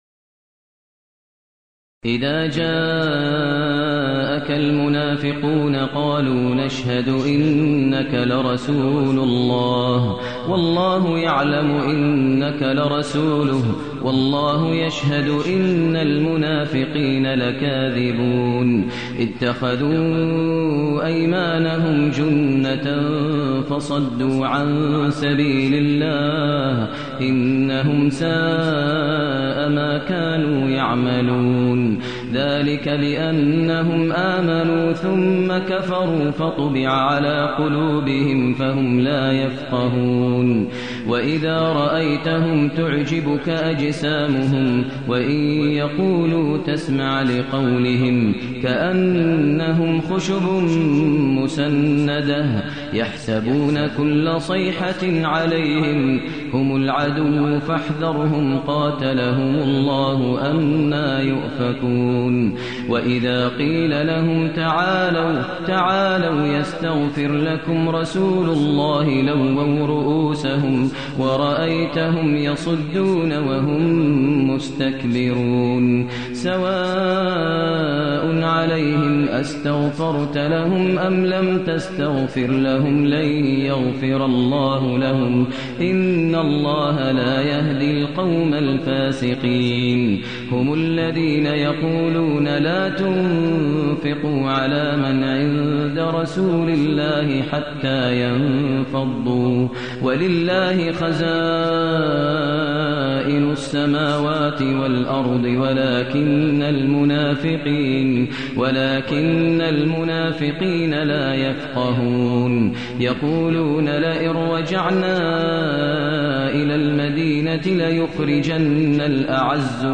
المكان: المسجد النبوي الشيخ: فضيلة الشيخ ماهر المعيقلي فضيلة الشيخ ماهر المعيقلي المنافقون